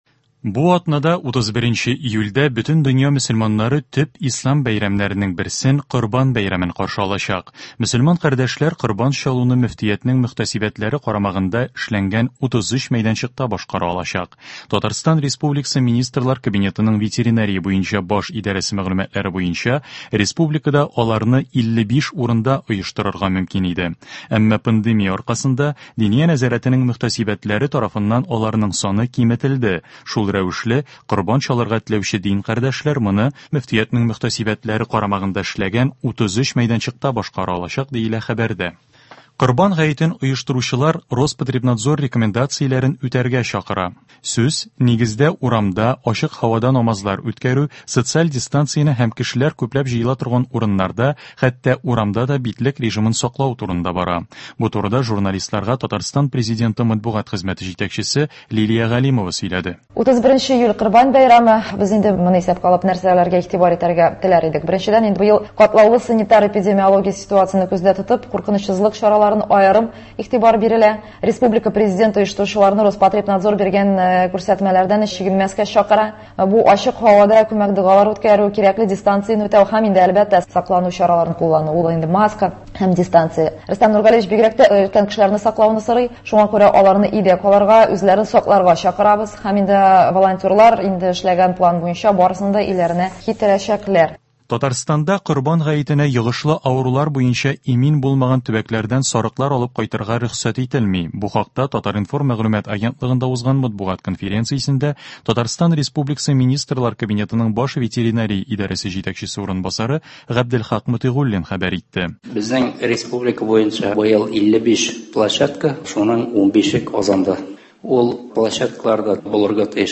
Яңалыклар. 27 июль.